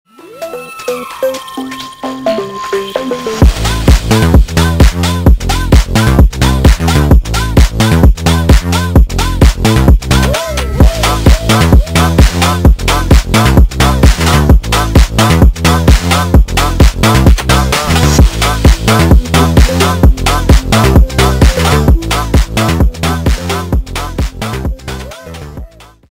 зарубежные без слов громкие клубные